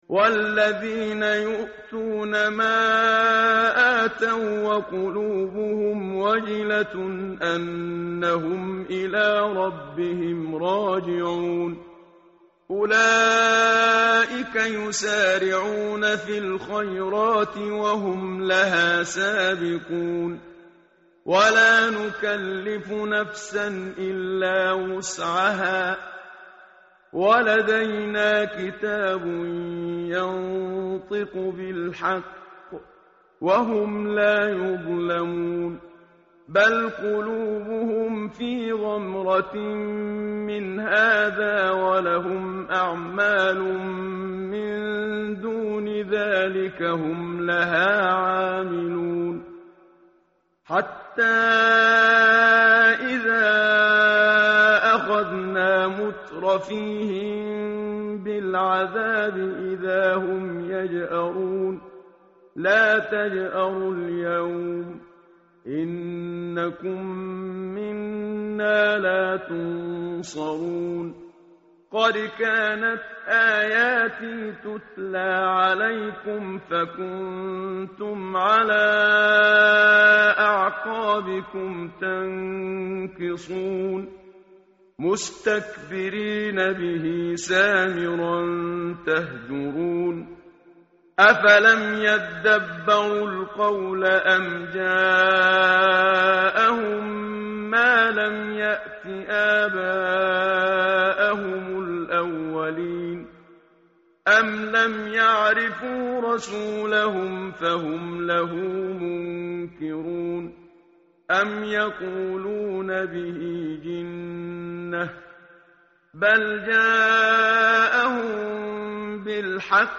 متن قرآن همراه باتلاوت قرآن و ترجمه
tartil_menshavi_page_346.mp3